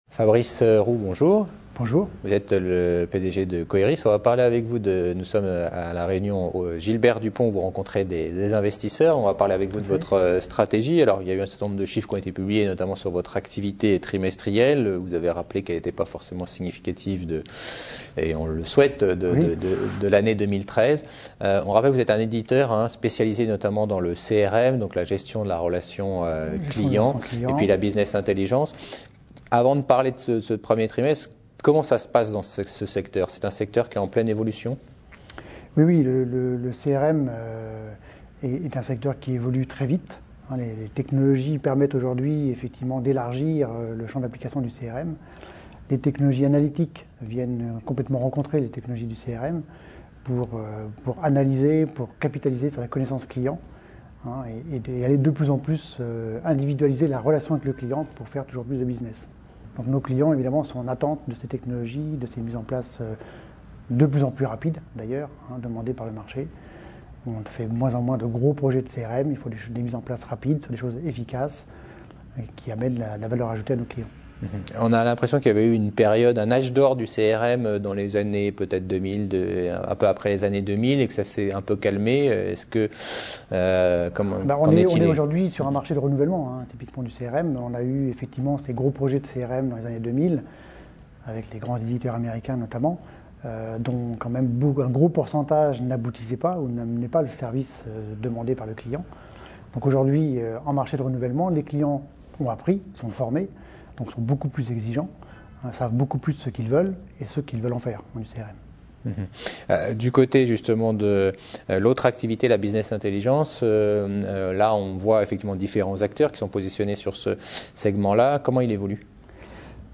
Stratégie et Perspectives : Interview